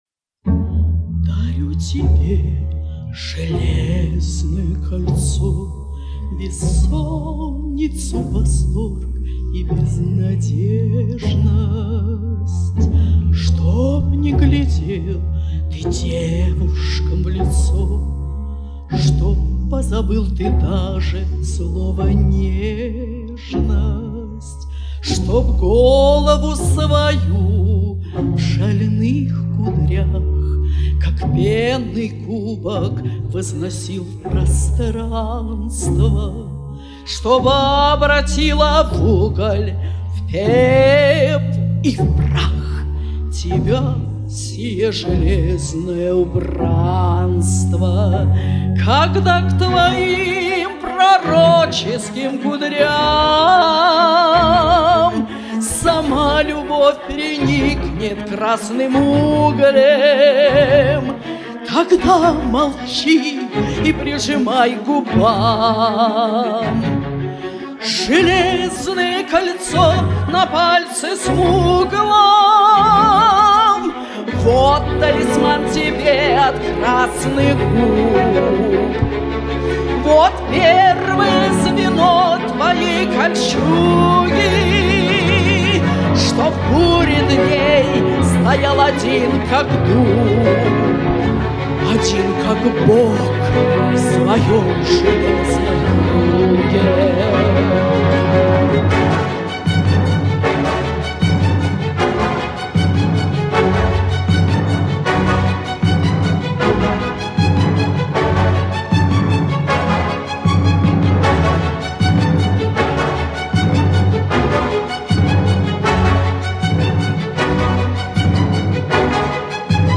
Оба исполнения сильны,великолепны!